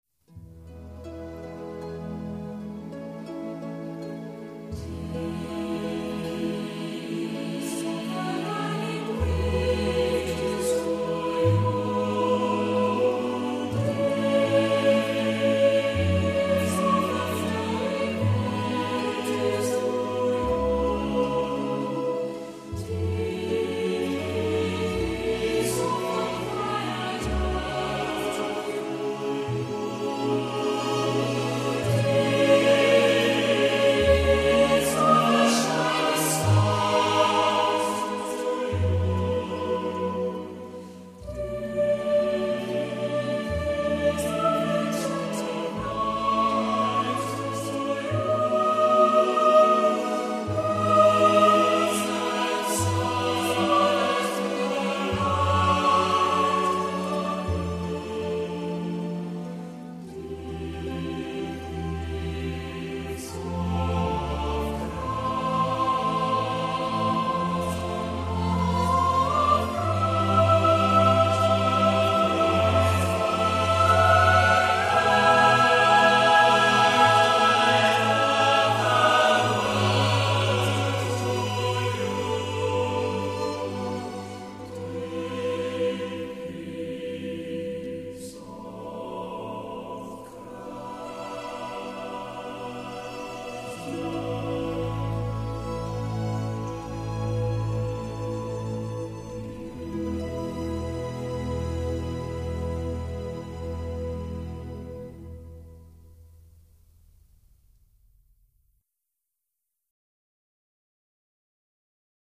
音乐风格: New Age / Celtic